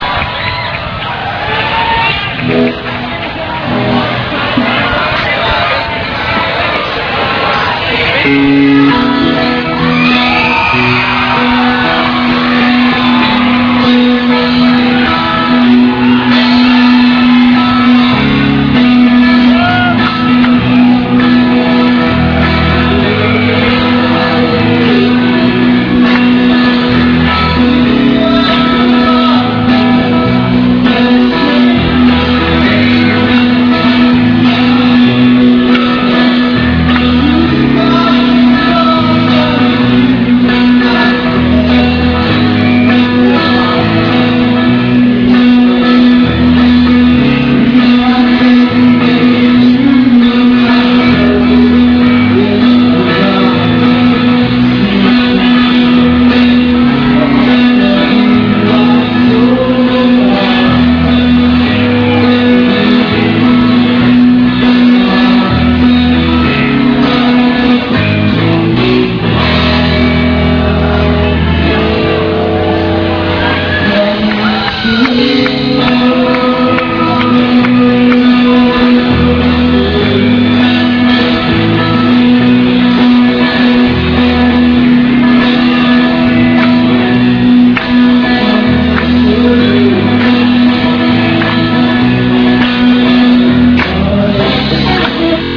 cover
vocals
lead guitar
rhythm guitar
bass
drums